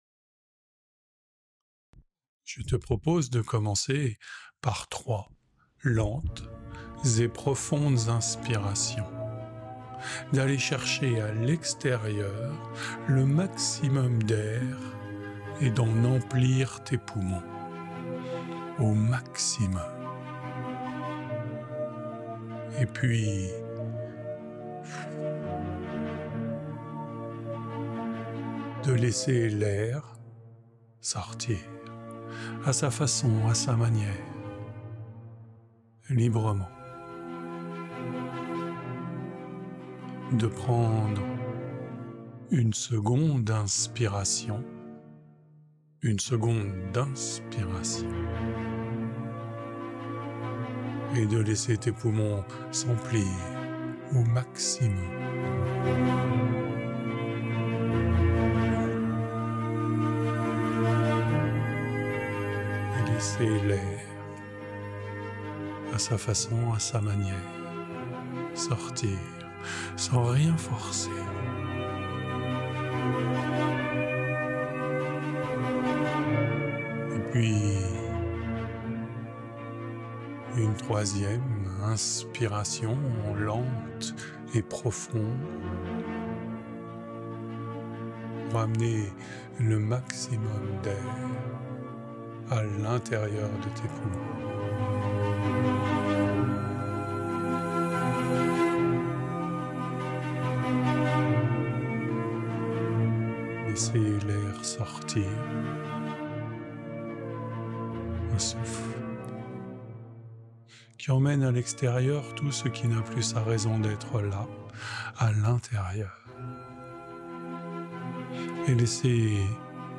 Hypnose (17') : Crée un espace intérieur apaisant en quelques respirations. Parfait pour surmonter une envie ou apaiser une émotion en douceur.